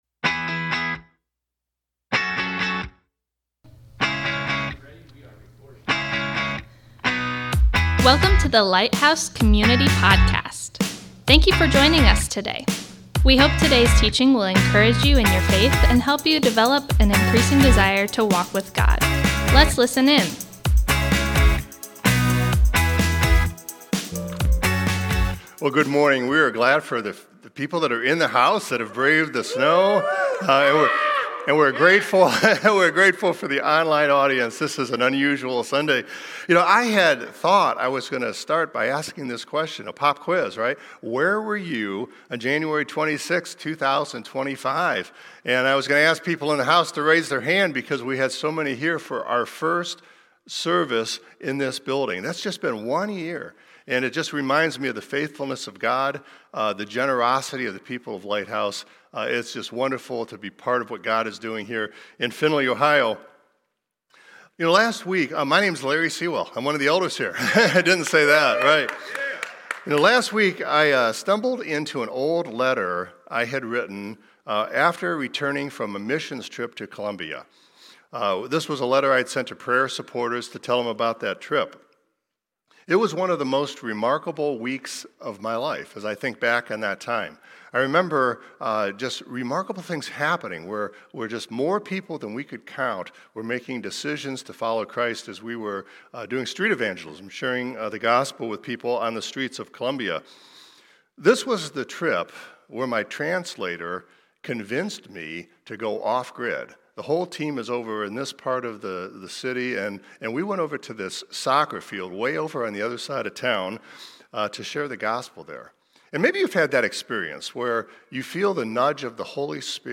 We’re kicking off a brand new teaching series called If Only I Knew. Over the next two weeks, we’ll be asking meaningful questions to help us see Scripture clearly and identify the clear marks of genuine faith.